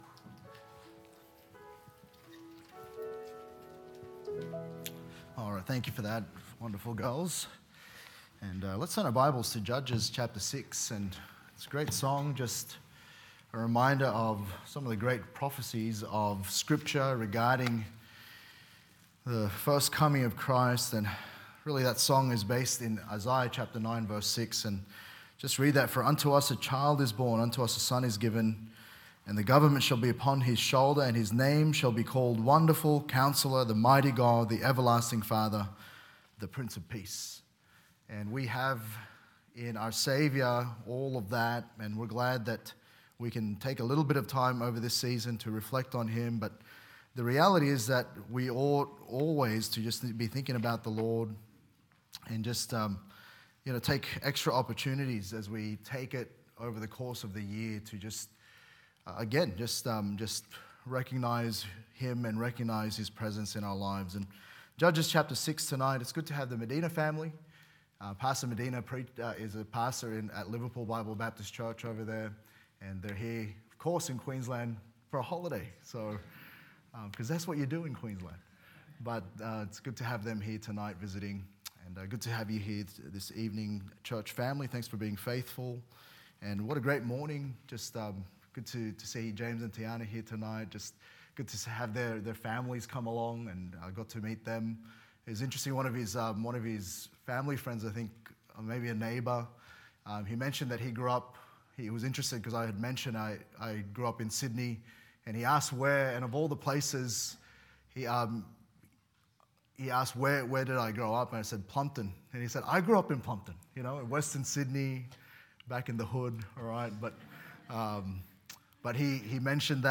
Sermons | Good Shepherd Baptist Church